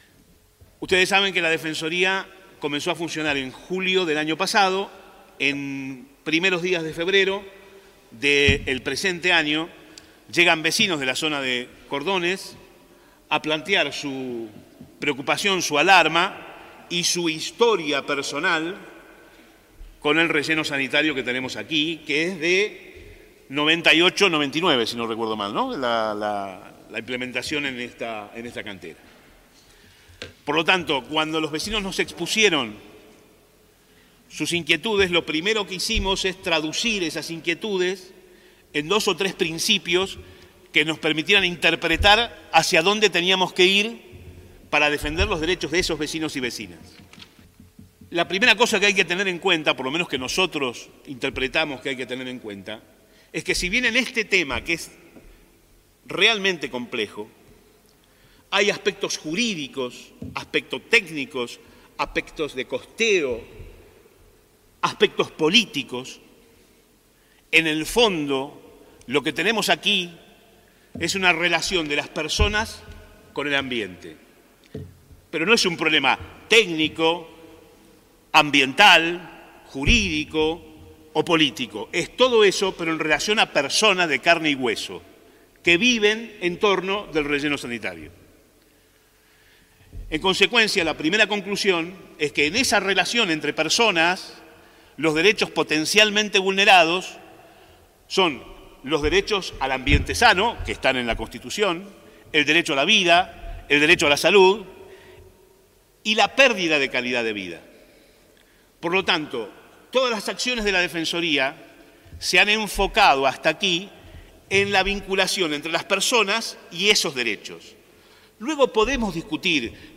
Estas son algunas de las voces que estuvieron presentes en la audiencia.
Defensor del Pueblo y del Ambiente de San Martín de los Andes, Fernando Bravo, hizo una introducción al rol de este organismo y detalla los puntos más destacados de cómo se llega a la actual situación.